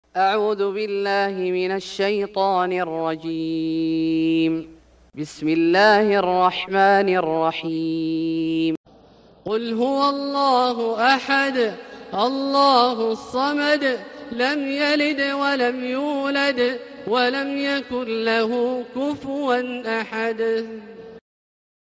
سورة الإخلاص Surat Al-Ikhlas > مصحف الشيخ عبدالله الجهني من الحرم المكي > المصحف - تلاوات الحرمين